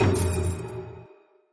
Appear_Total_Win_Sound.mp3